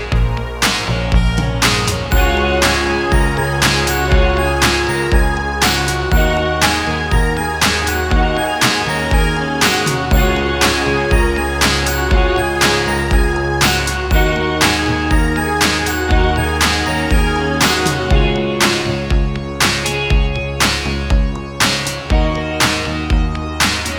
Cut Down With No Backing Vocals Pop (1980s) 3:18 Buy £1.50